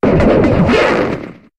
Cri de Darumacho Mode Normal dans Pokémon HOME.